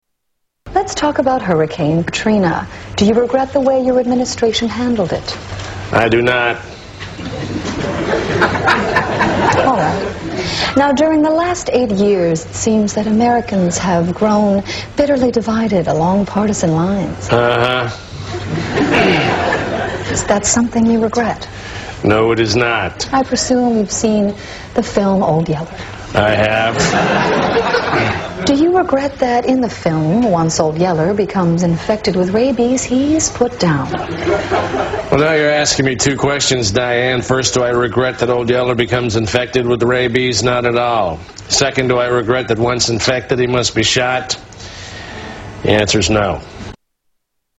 Darrell Hammond does Dick Cheney
Category: Comedians   Right: Personal
Tags: Comedians Darrell Hammond Darrell Hammond Impressions SNL Television